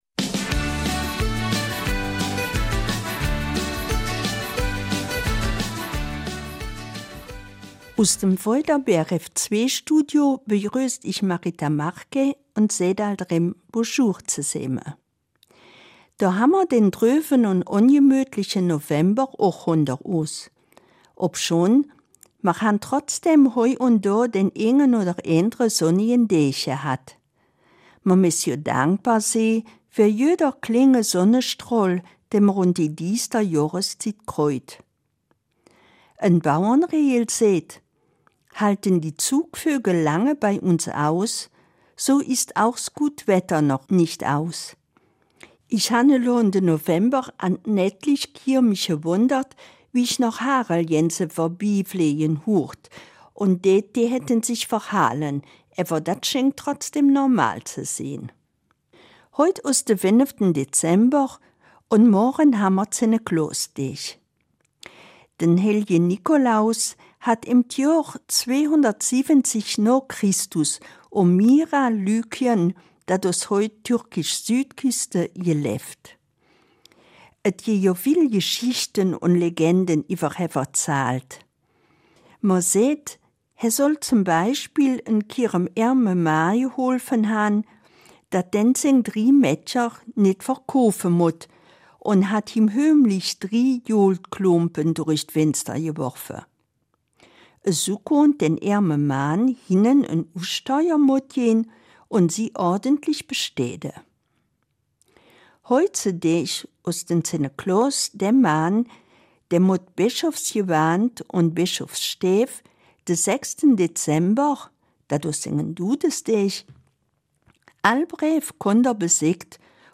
Eifeler Mundart: Adventszauber